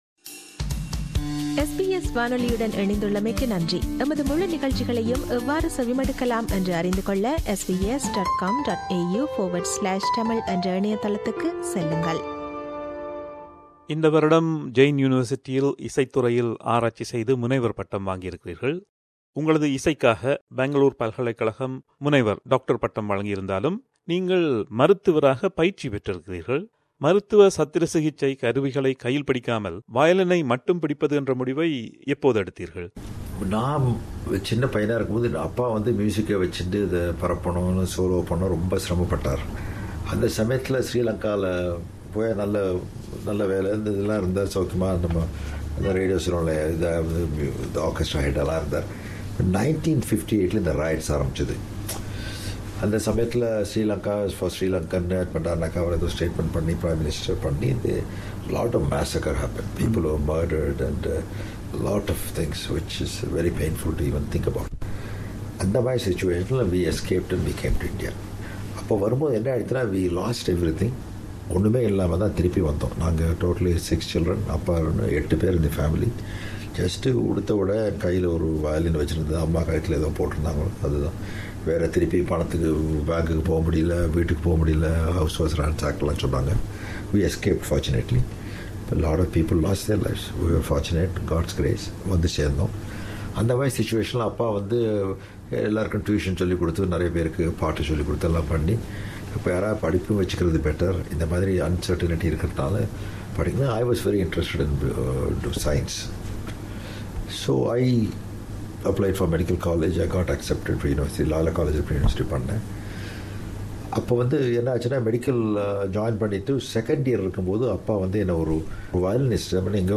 In the second part of the long interview, he speaks on why he went through the training to be a physician and his work as a composer for cinema.